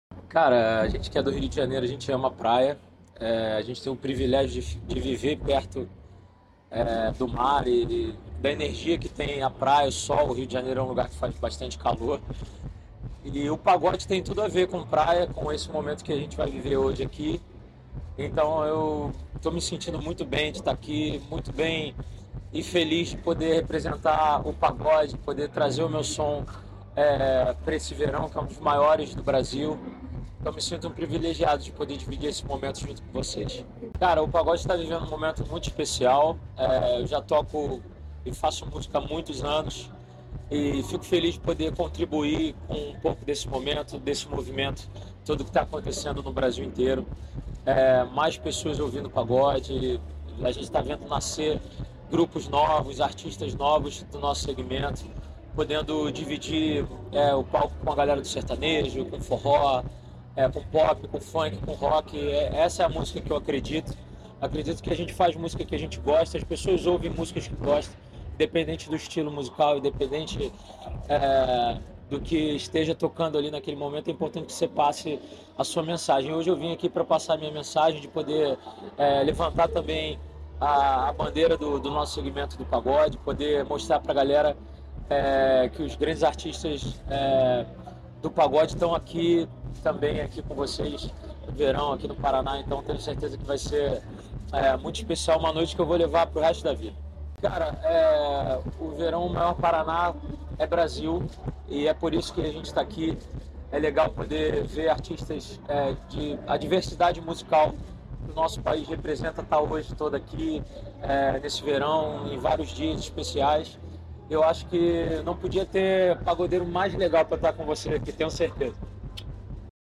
Sonora do cantor Dilsinho sobre o show em Pontal do Paraná no Verão Maior Paraná